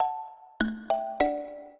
mbira
minuet3-4.wav